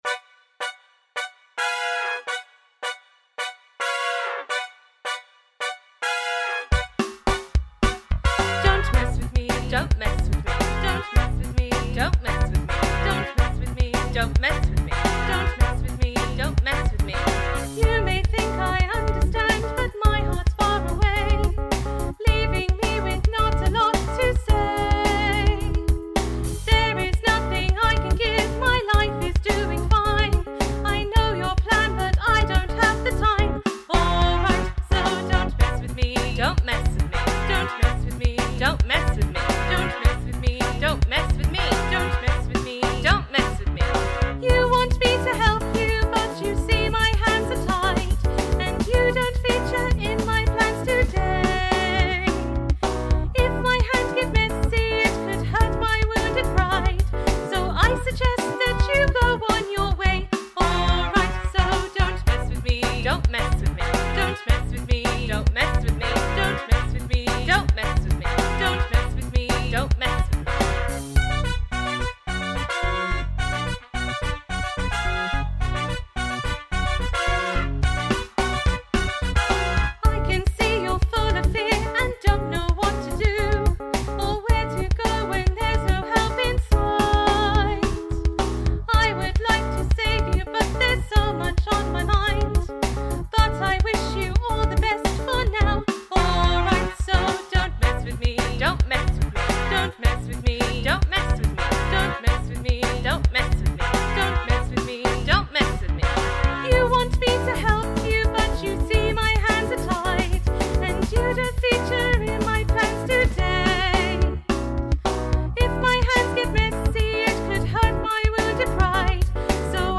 Downtown Funk - Vocals